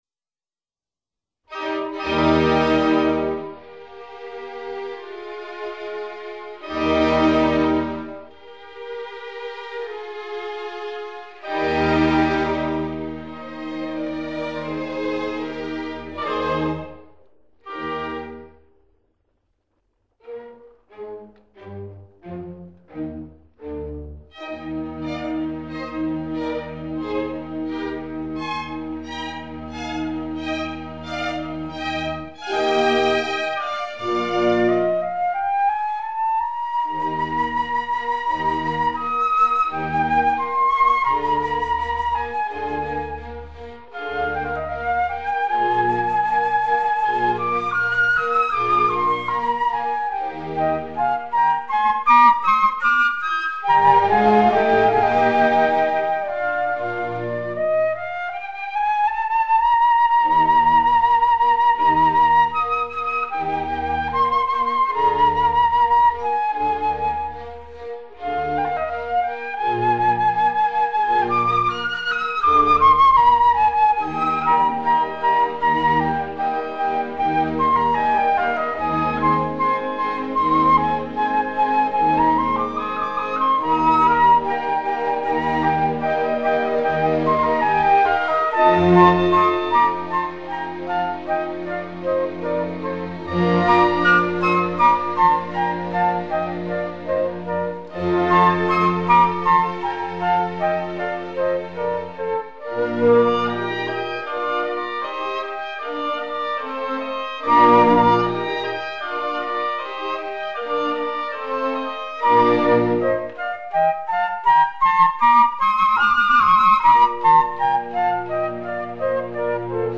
Concerto pour deux flûtes - 2e mvt : Largo